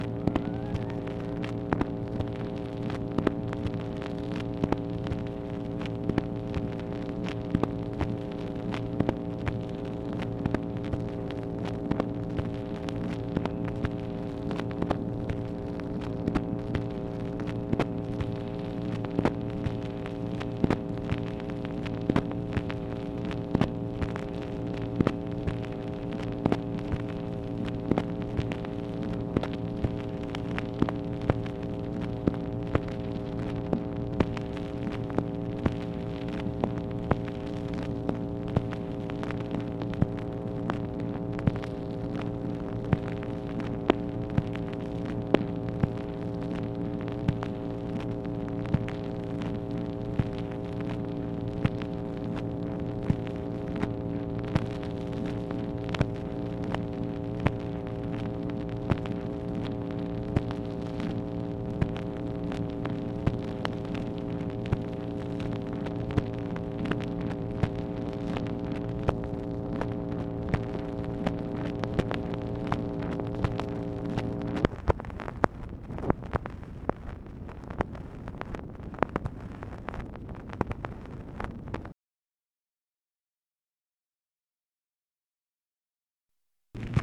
MACHINE NOISE, August 26, 1964
Secret White House Tapes | Lyndon B. Johnson Presidency